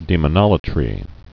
(dēmə-nŏlə-trē)